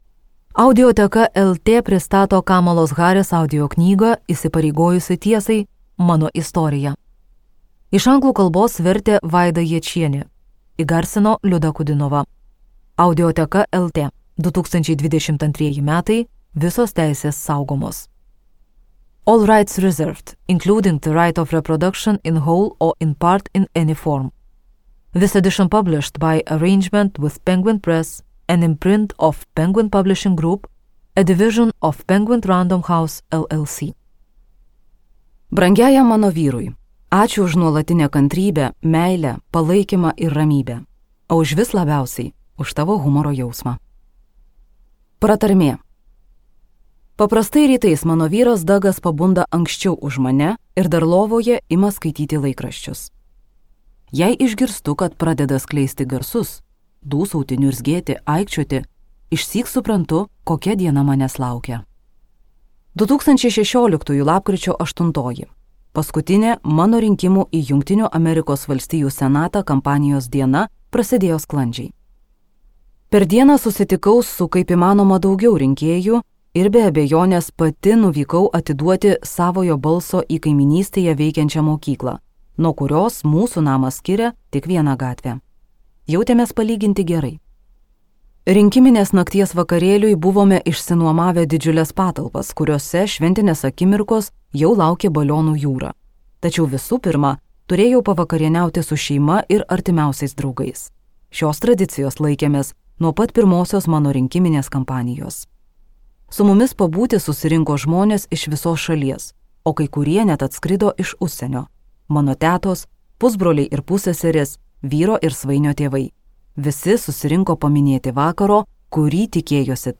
Skaityti ištrauką play 00:00 Share on Facebook Share on Twitter Share on Pinterest Audio Įsipareigojusi tiesai.